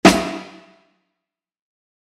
Plattenhall
Plattenhall.mp3